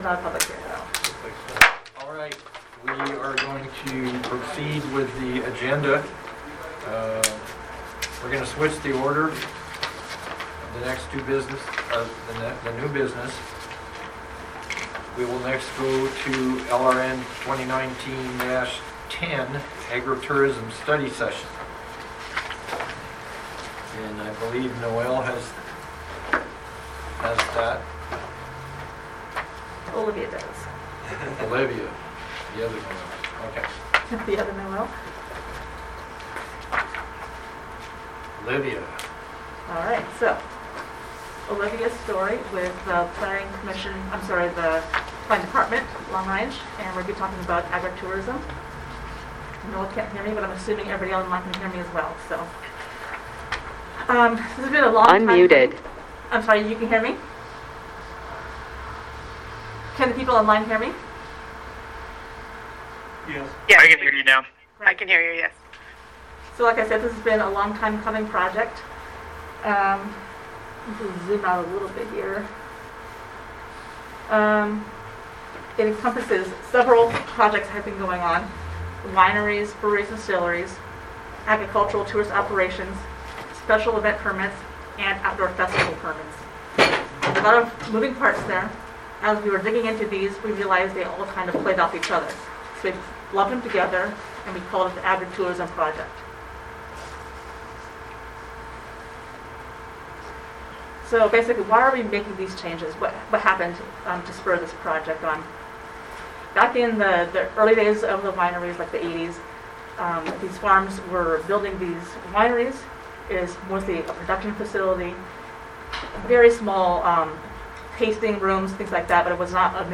Work session 1 October 13, 2021 6:00 P.M.